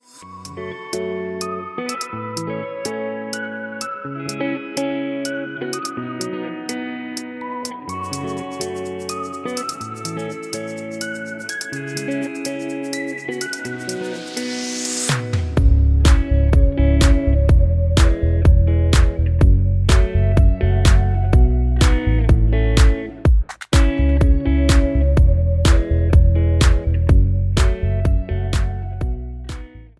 C#m
MPEG 1 Layer 3 (Stereo)
Backing track Karaoke
Pop, 2010s